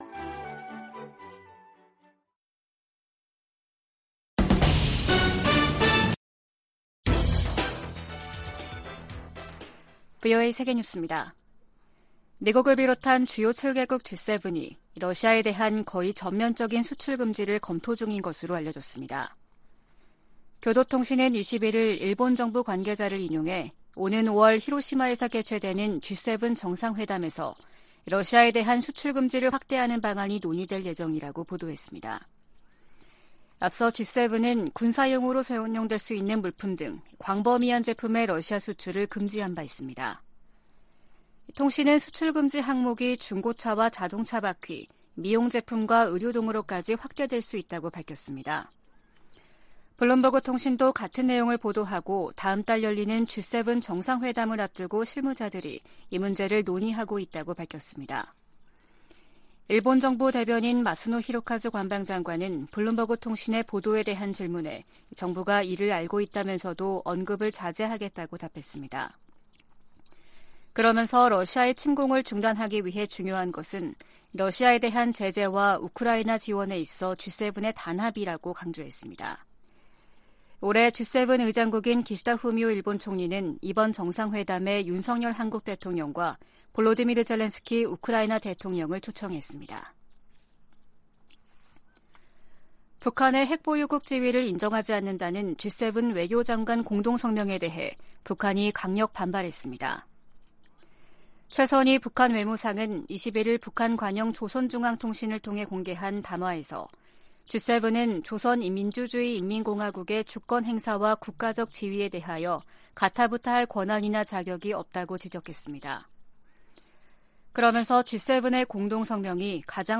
VOA 한국어 아침 뉴스 프로그램 '워싱턴 뉴스 광장' 2023년 4월 22일 방송입니다. 백악관은 윤석열 한국 대통령의 국빈 방문 기간 중 북한·중국 문제가 논의될 것이라고 밝혔습니다.